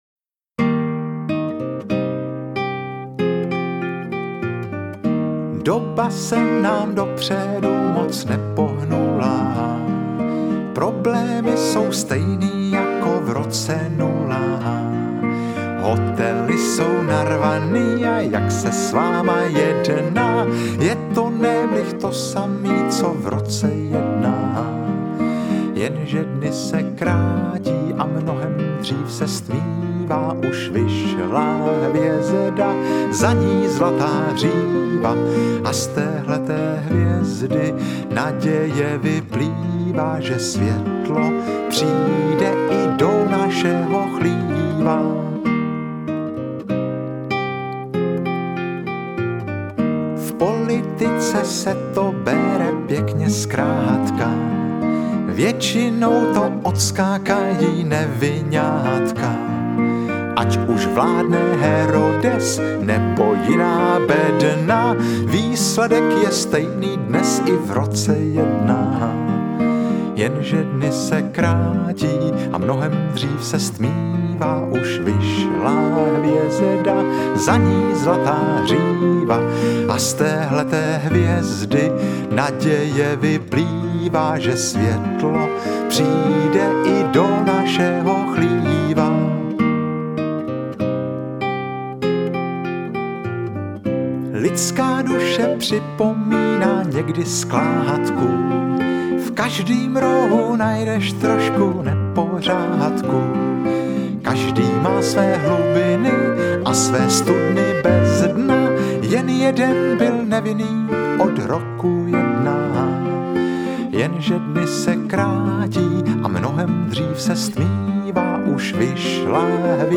Interpreti:  Oldřich Kaiser, Jiří Lábus, Josef Somr, Jan Šťastný, Miroslav Vladyka
Povídky jsou doplněny písničkami Marka Ebena, Ondřeje Suchého, Jana Jiráně a Jiřího Schmitzera.